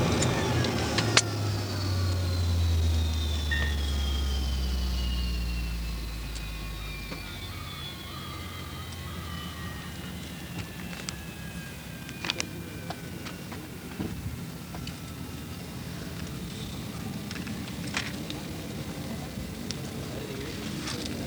RR_Spey_shutdown.wav